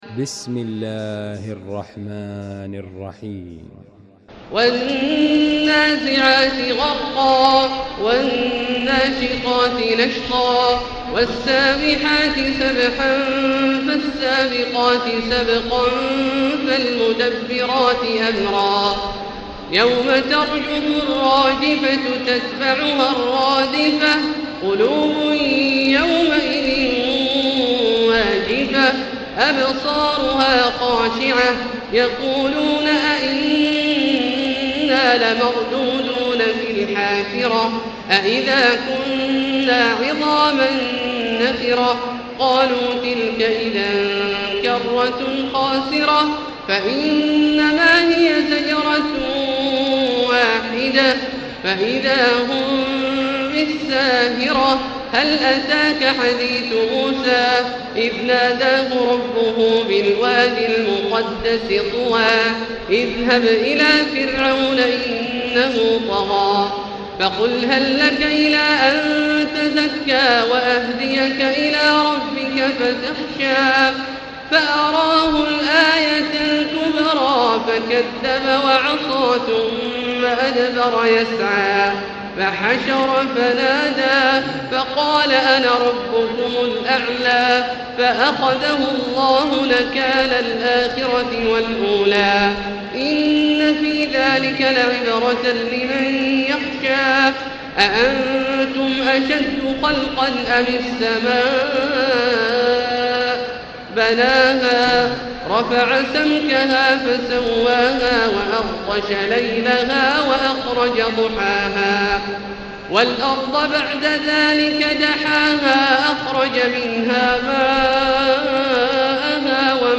المكان: المسجد الحرام الشيخ: فضيلة الشيخ عبدالله الجهني فضيلة الشيخ عبدالله الجهني النازعات